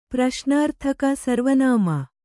♪ praśnārthaka sarva nāma